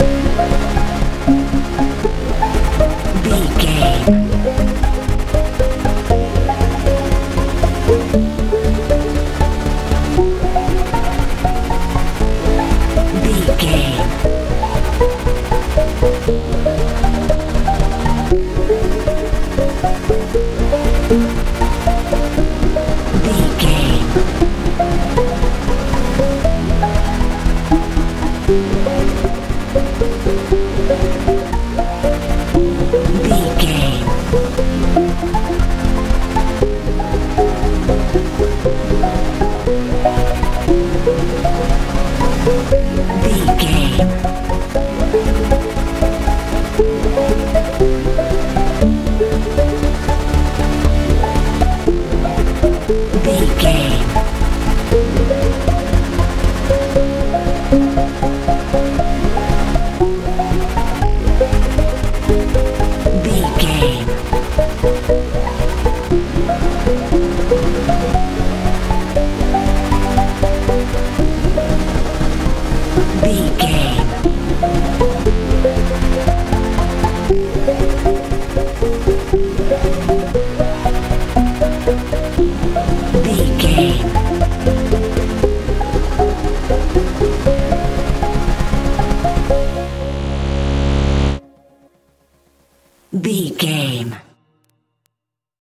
modern pop feel
Ionian/Major
C♯
magical
mystical
synthesiser
bass guitar
drums
80s
strange
suspense
high tech